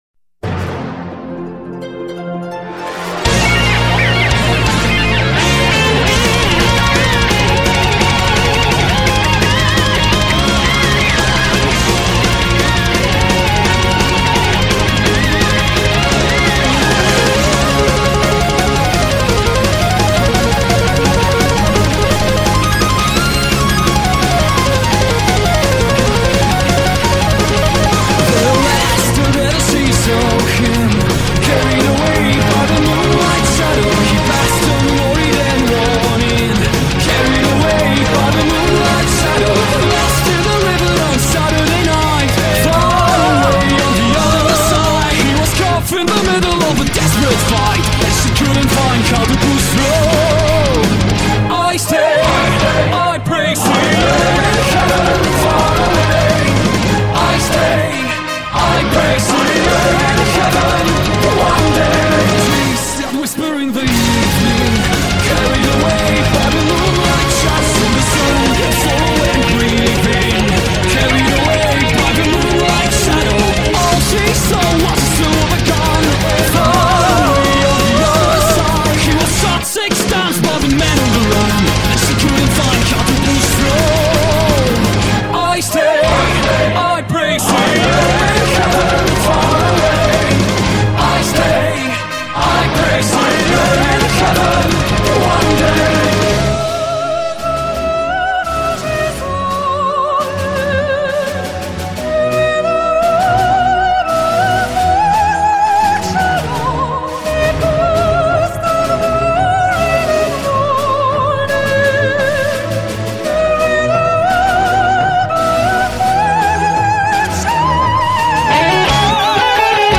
Но качество...
Извиняюсь, конвертировал с ютуба, а там лучше не было.